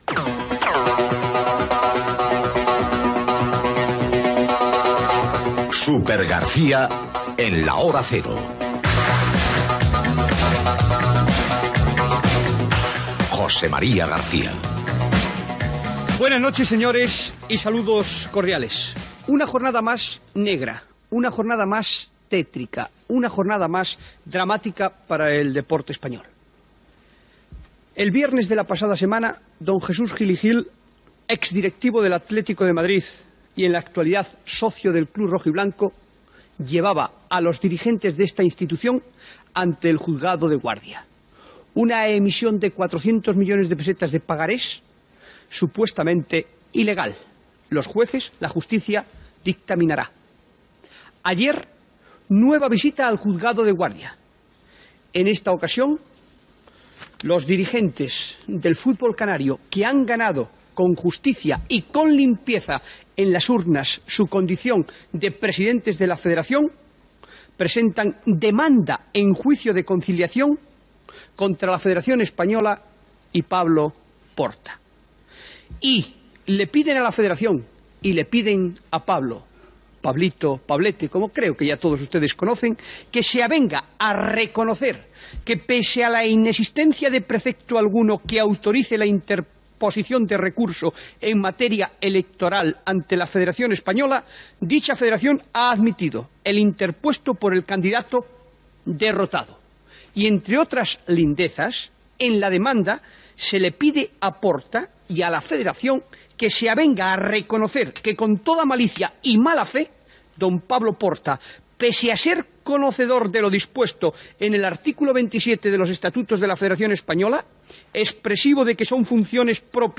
dfbea6853a5608df0ce91da157dcdecad24fa93b.mp3 Títol Antena 3 Radio Emissora Televisión Española Cadena Antena 3 Radio Titularitat Privada estatal Nom programa Supergarcía en la hora cero Descripció Espai especial fet a TVE.
Entrevista al futbolista Hipólito Rincón.
Esportiu